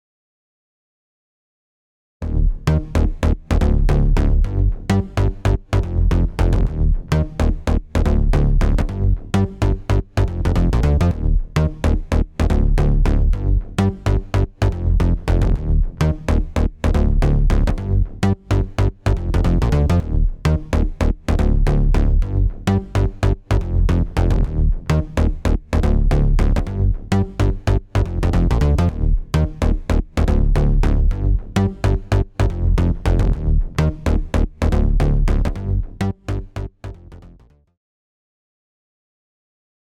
特にベースに使うと、ミックス全体の低音が強くなり、存在感がアップします
OFF（ベース単体）